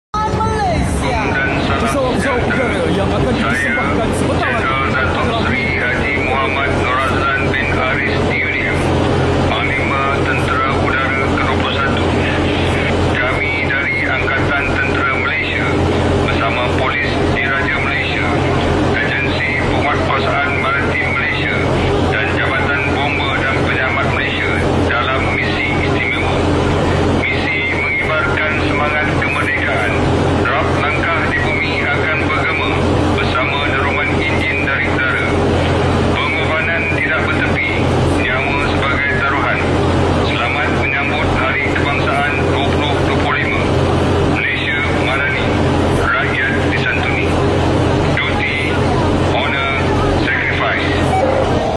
Ucapan Jeneral PTU Dato Seri sound effects free download